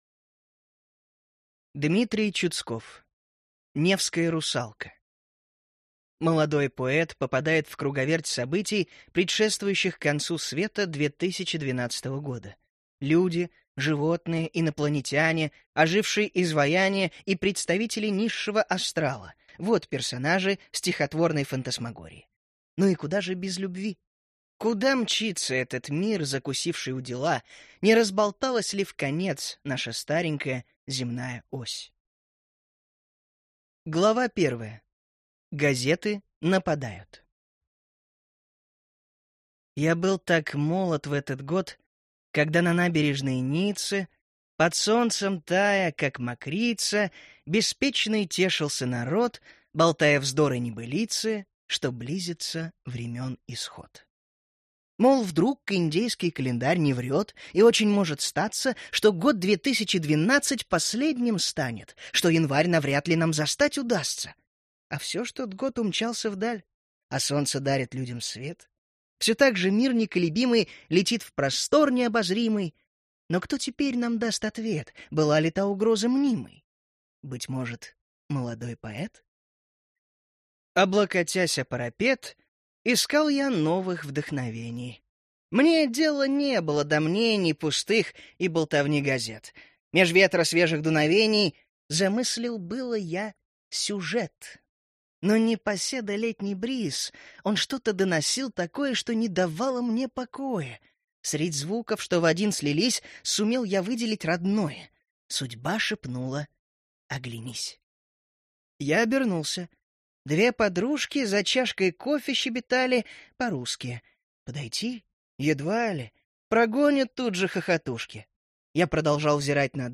Аудиокнига Невская русалка | Библиотека аудиокниг